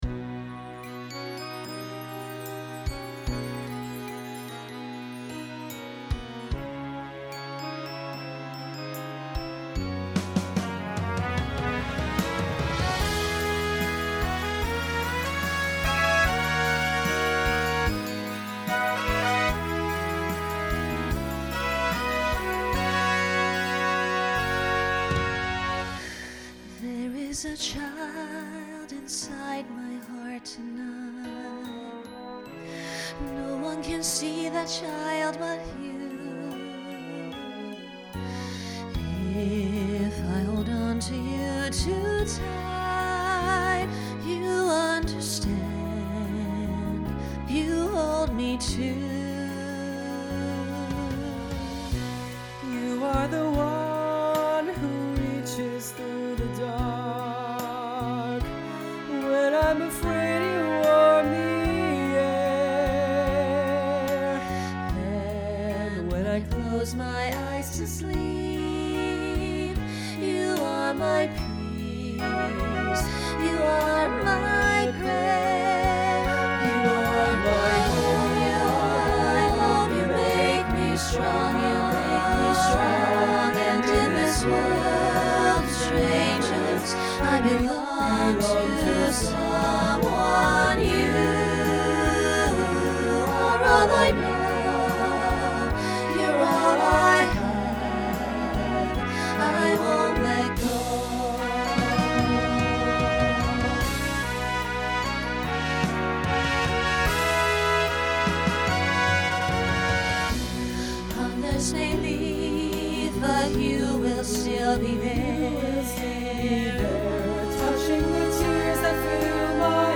Voicing SATB
Genre Broadway/Film
Ballad